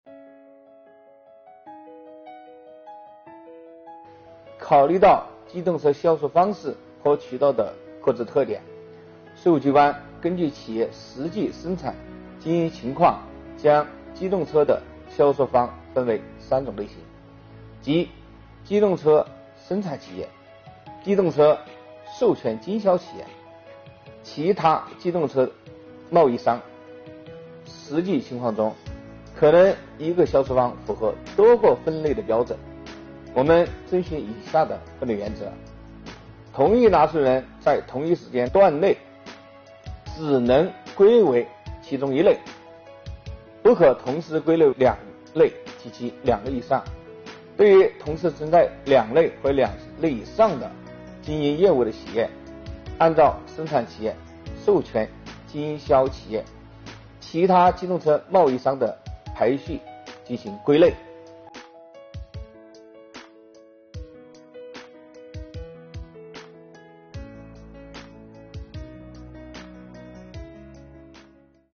近日，国家税务总局推出“税务讲堂”课程，国家税务总局货物和劳务税司副司长张卫详细解读《机动车发票使用办法》相关政策规定。